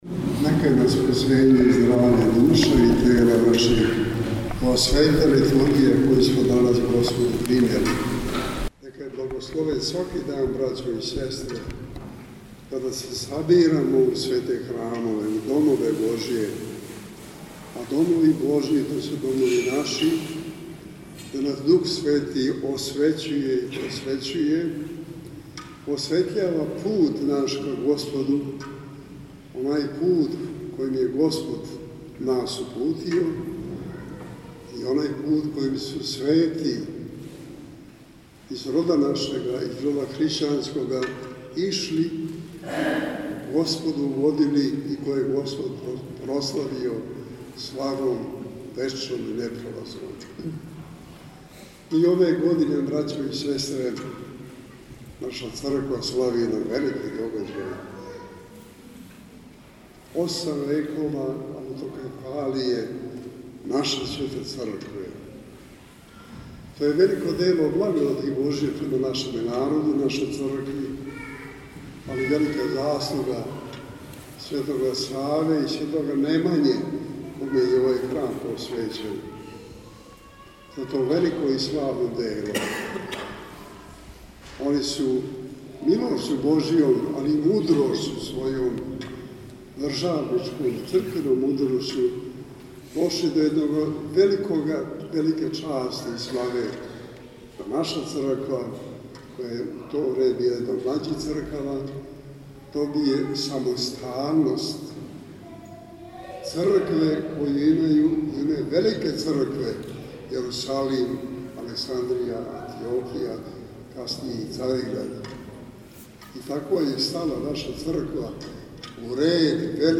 Његова Светост Патријарх српски Иринеј, служио је данас, 13. октобра 2019. године, Свету Архијерејску Литургију у храму Светог Симеона Мироточивог на Новом Београду.